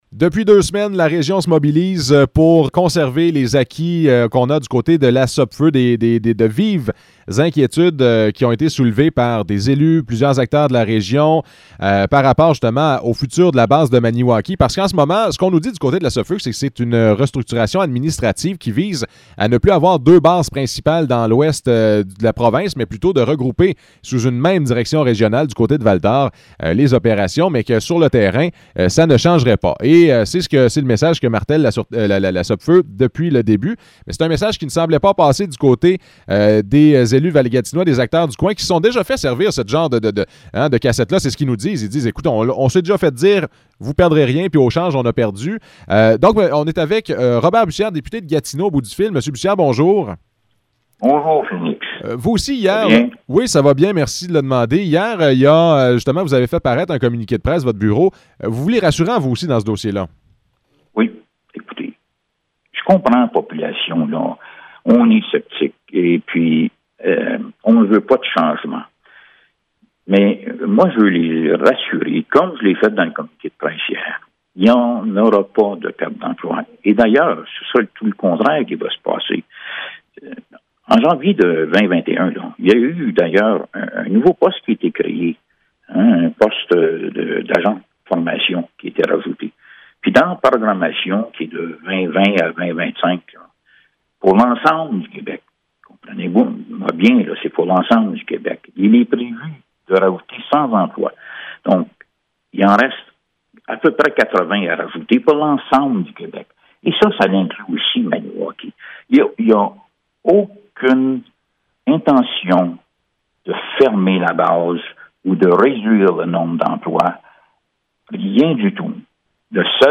Entrevue avec Robert Bussière